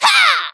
nita_atk_02.wav